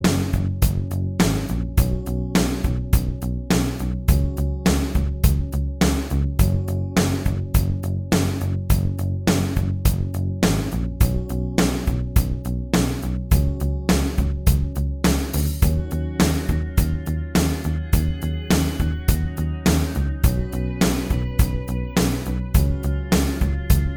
Minus Guitars Pop (1980s) 4:04 Buy £1.50